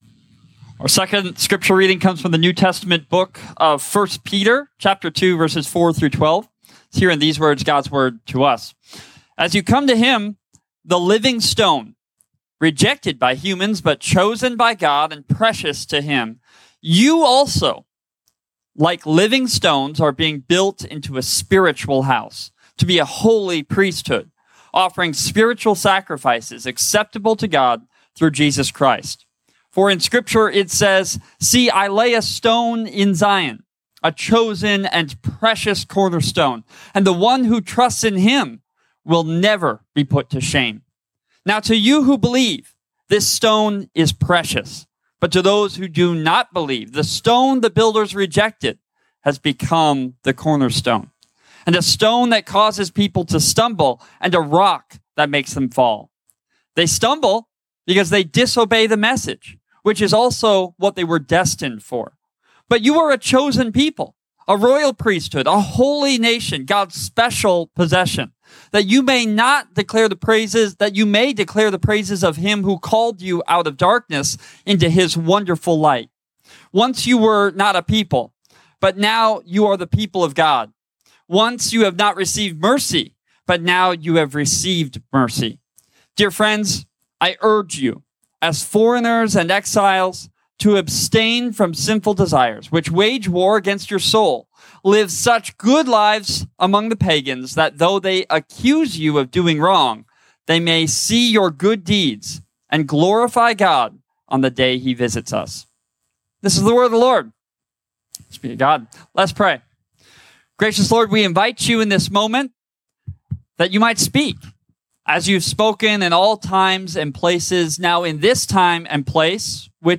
A message from the series "Reformed Worship."
The 8:50 worship service at First Presbyterian Church in Spirit Lake.